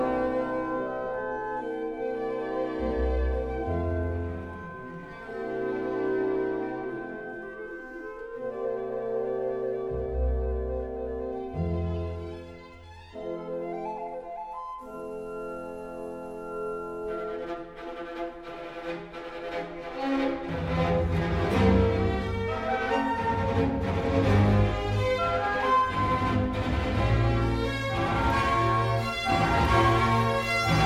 Première symphonie écrite pour grand orchestre